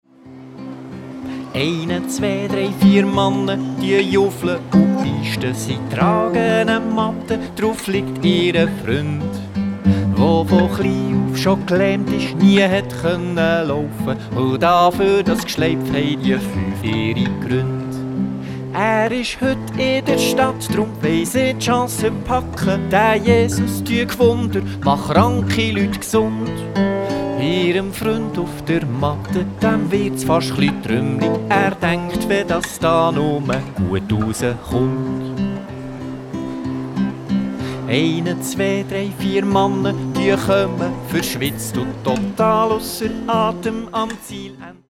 Und natürlich machen wieder Kinder mit!